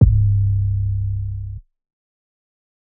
Metro 808s [South].wav